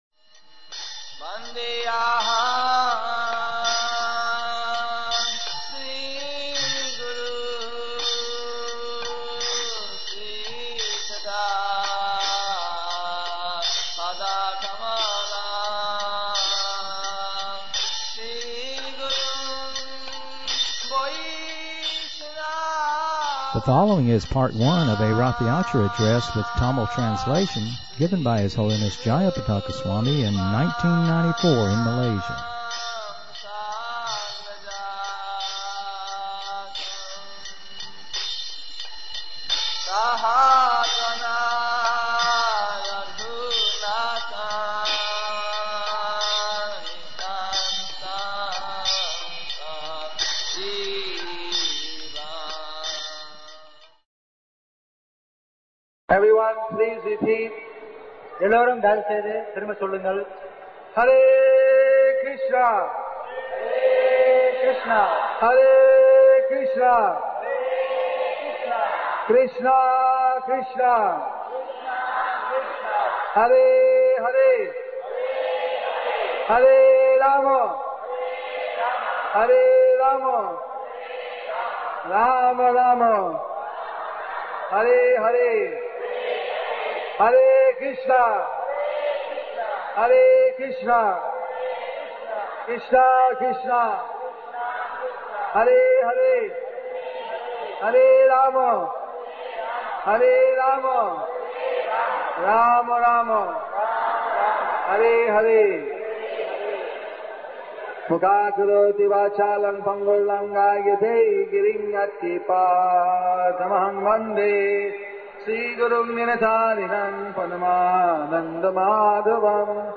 1994 Ratha Yatra Addressing With Tamil Translation Part 1 @ Malaysia